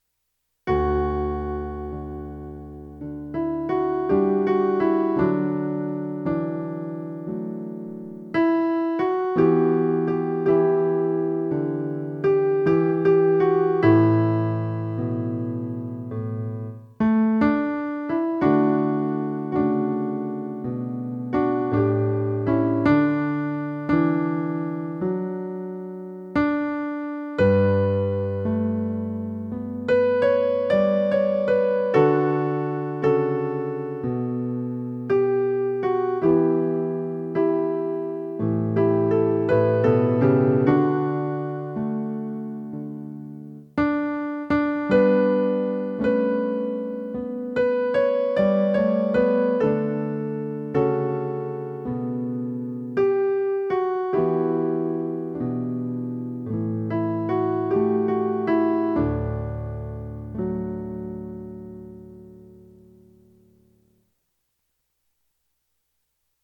Lord, You Have Come - Soprano
LordYouHaveCome_Soprano.mp3